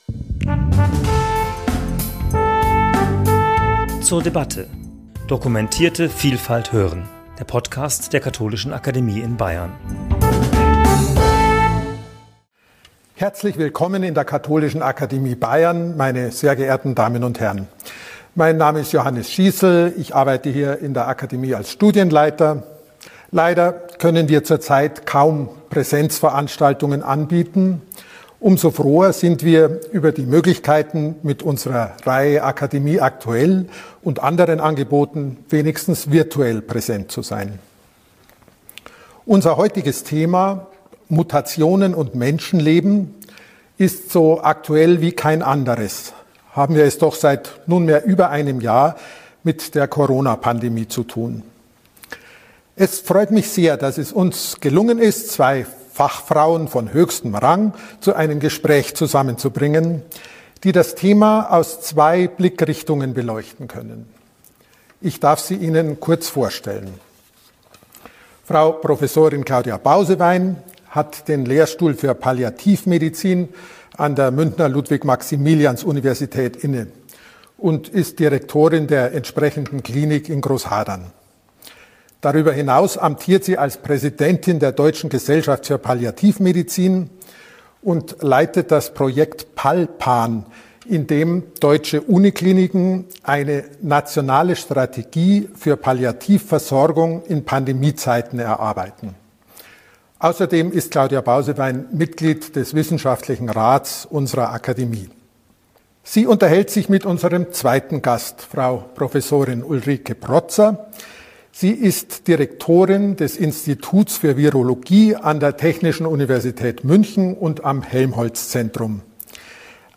Gespräch zum Thema 'Mutationen und Menschenleben' ~ zur debatte Podcast